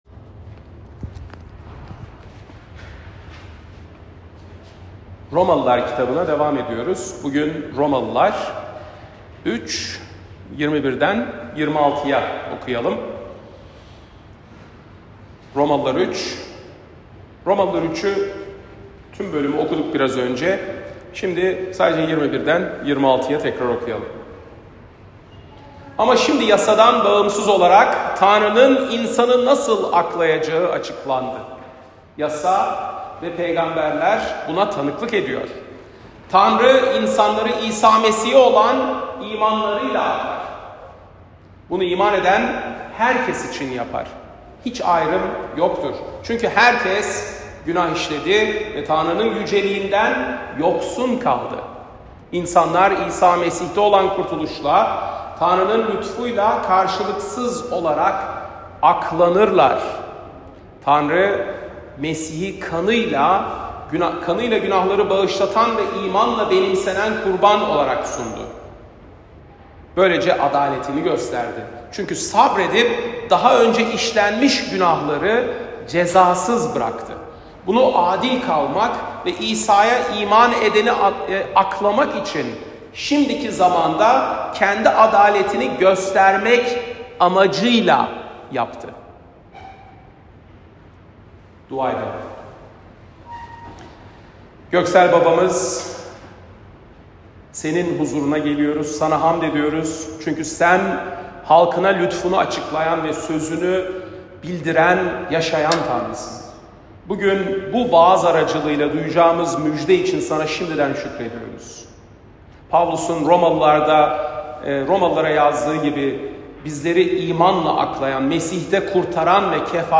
Pazar, 16 Şubat 2025 | Romalılar Vaaz Serisi 2024-26, Vaazlar